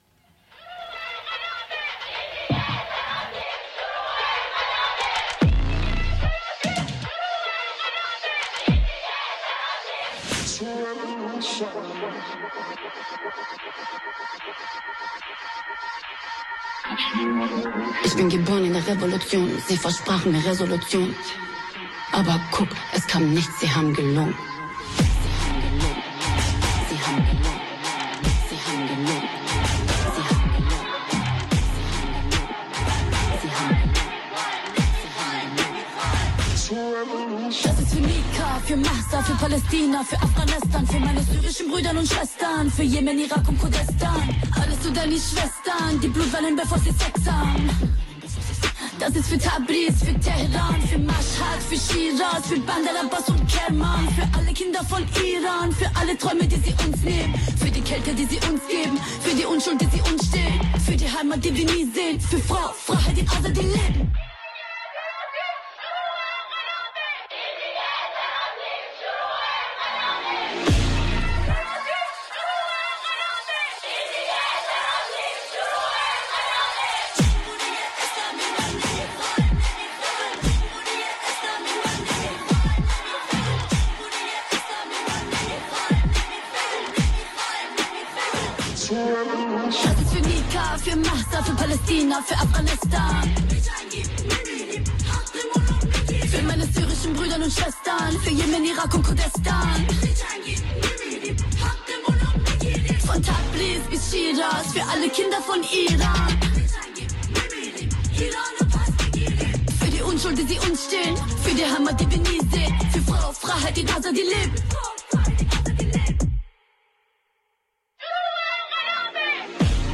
In questo episodio di Harraga , in onda su Radio Blackout ogni venerdì dalle 15 alle 16, parliamo di deportazioni con un compagno gambiano del collettivo Solimo – Solidarity Movement, basato a Berlino.